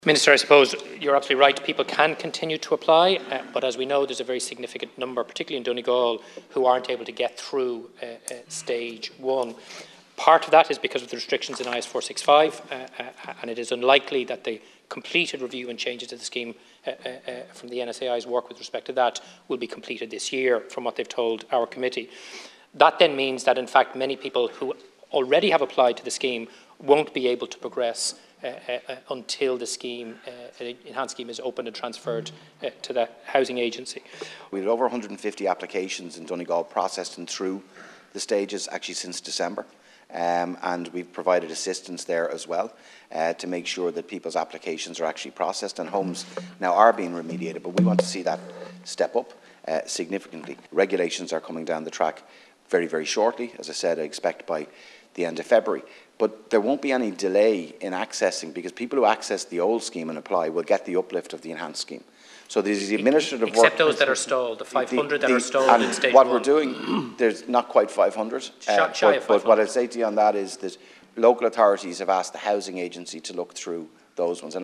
Minister O’Brien in responding to Deputy Eoin O’Brion says people engaging with the current scheme will benefit from the enhanced regulations: